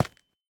Minecraft Version Minecraft Version snapshot Latest Release | Latest Snapshot snapshot / assets / minecraft / sounds / block / calcite / step5.ogg Compare With Compare With Latest Release | Latest Snapshot
step5.ogg